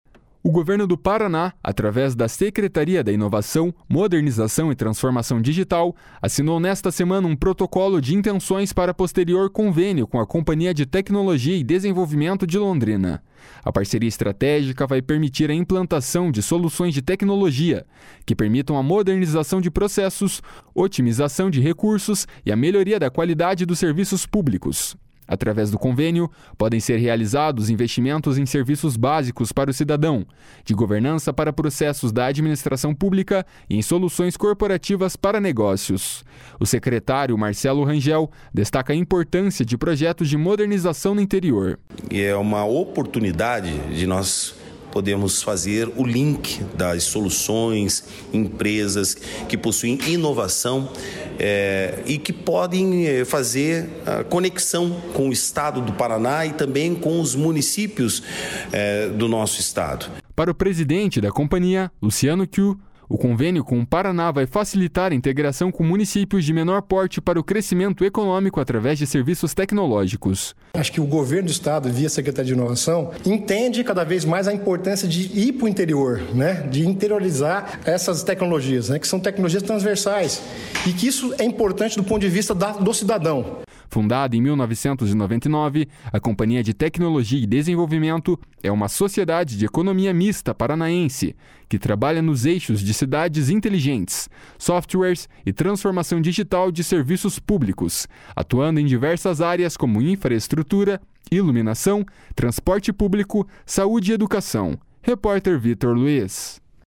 O secretário Marcelo Rangel destaca a importância de projetos de modernização no Interior. // SONORA MARCELO RANGEL //